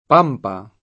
pampa [p#mpa] s. f.; pl. ‑pe — nome di vaste pianure sudamericane: le pampe dell’immenso Plata [le p#mpe dell imm$nSo pl#ta] (Pascoli) — sp. pampa [